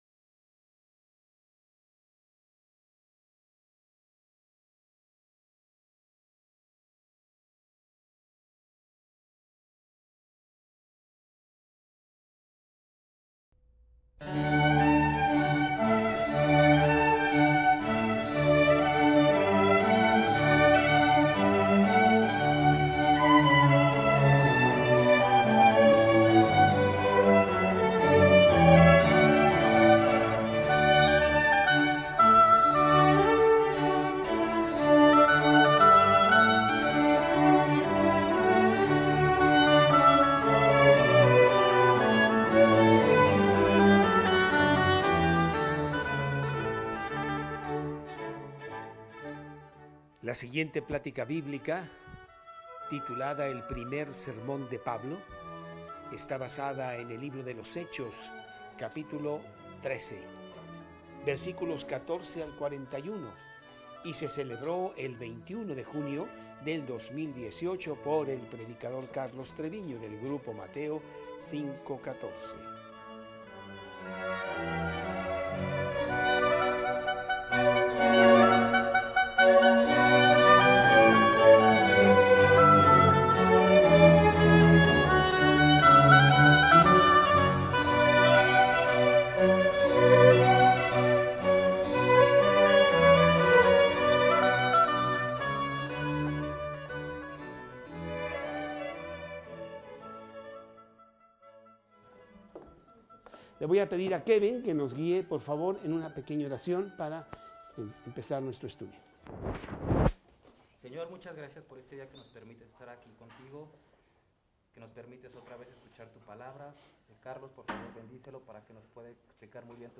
Primer Sermón de Pablo